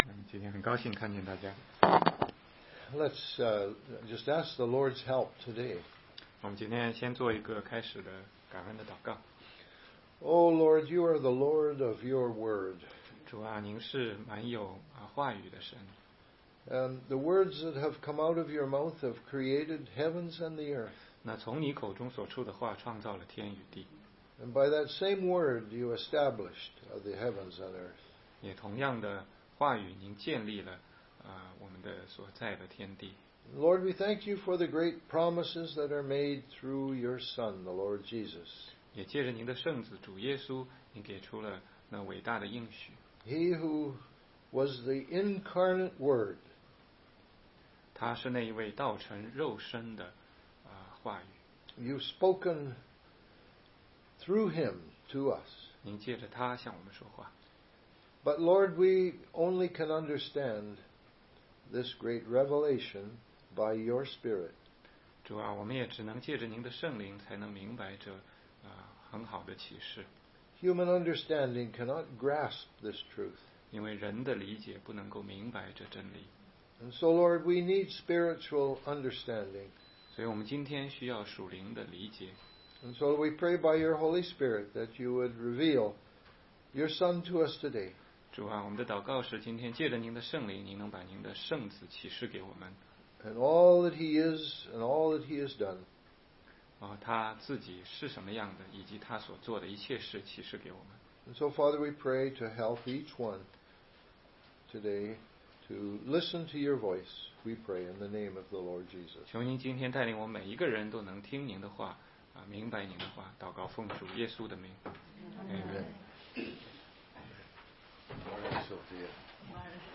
16街讲道录音 - 约翰福音10章救恩永不会失去之三